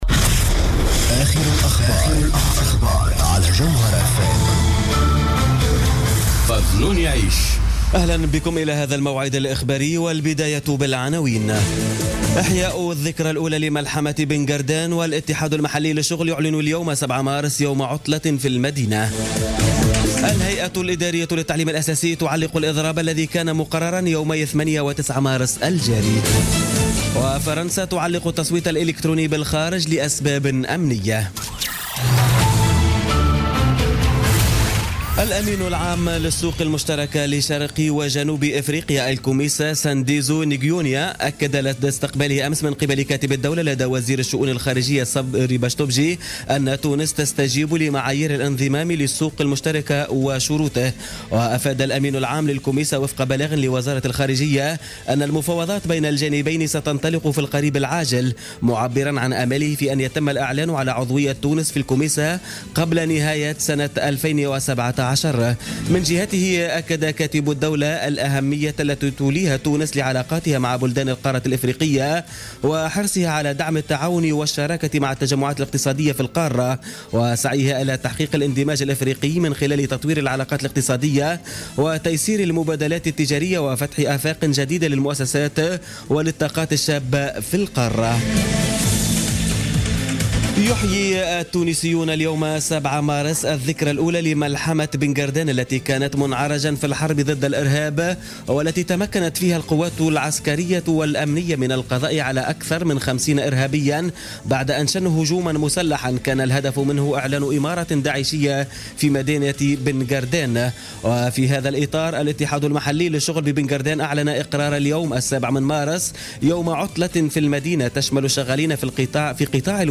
نشرة أخبار منتصف الليل ليوم الثلاثاء 7 مارس 2017